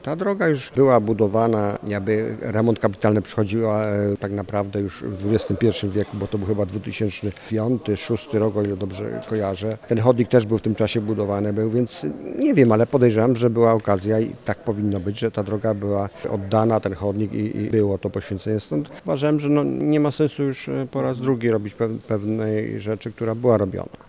Głos w tej sprawie zabrał starosta mielecki Stanisław Lonczak.